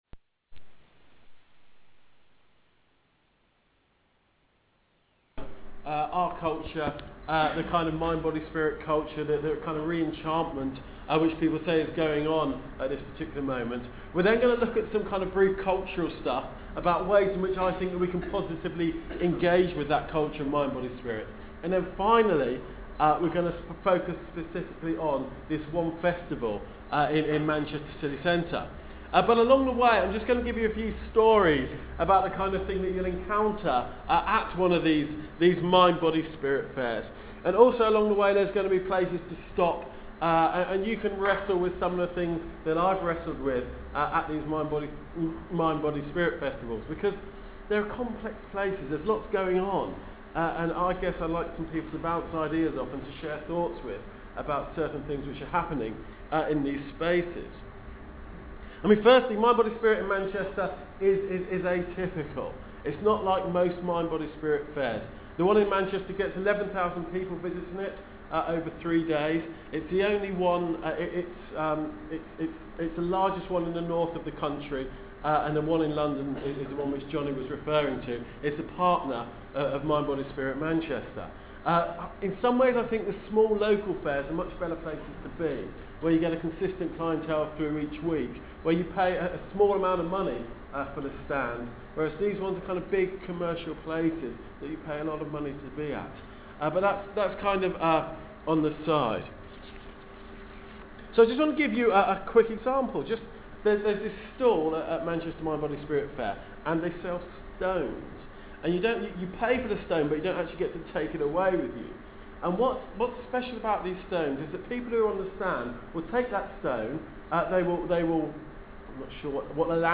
i love what they are doing engaging with the mind body spirit fair in manchester and the search for spirituality that is widespread in the culture. i recorded it on my italk – quality isn’t brilliant but if you are inetersted you can download it here (11mb mp3) .